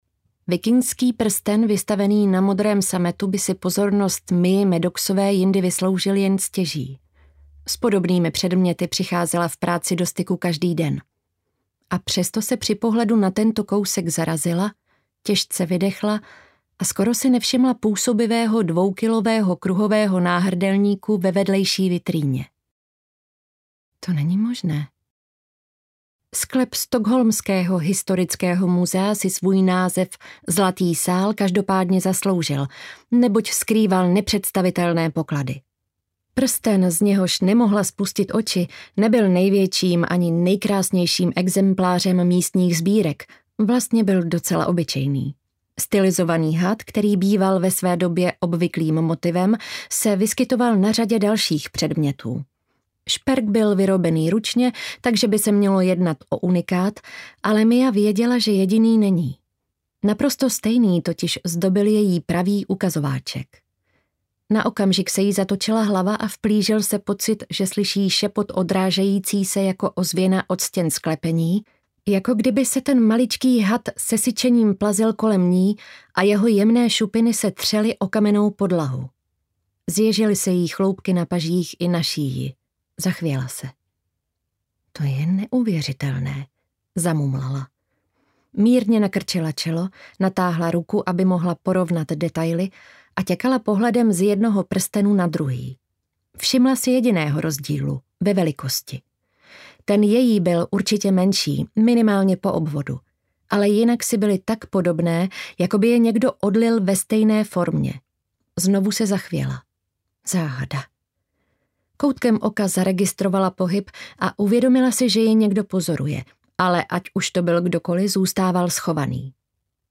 Ozvěny run audiokniha
Ukázka z knihy